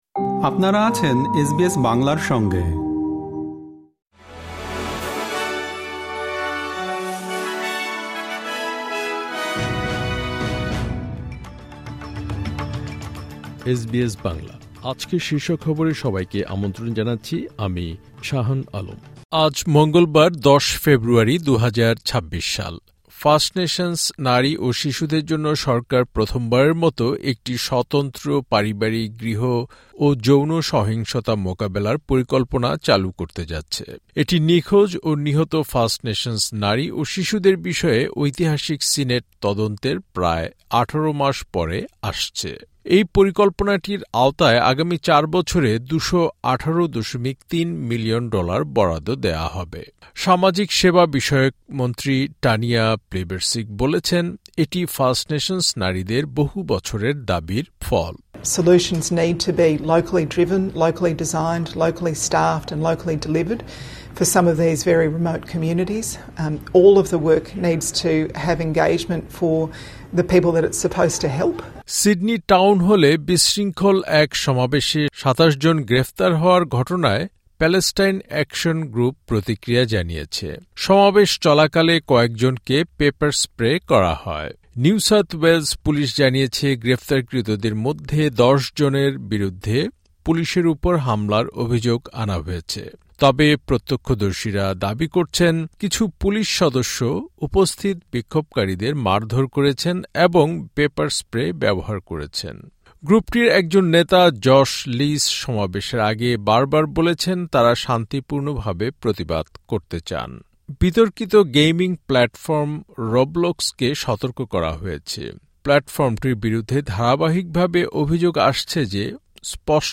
আজকের শীর্ষ খবর